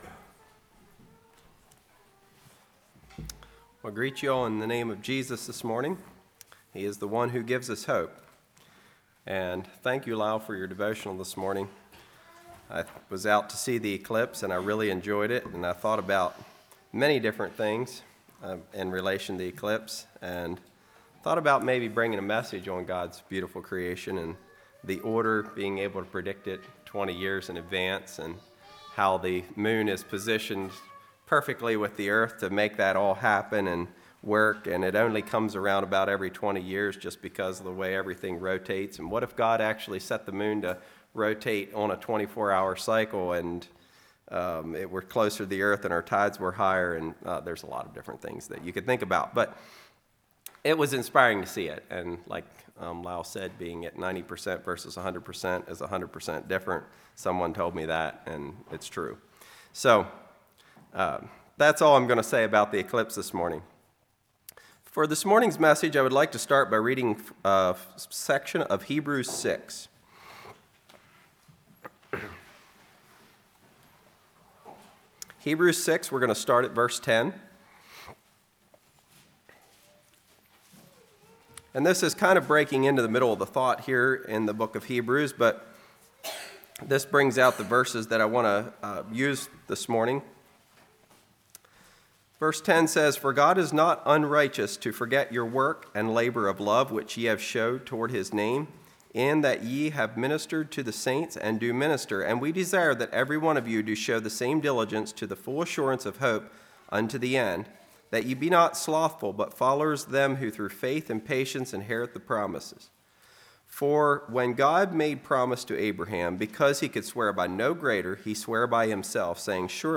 Congregation: Keysville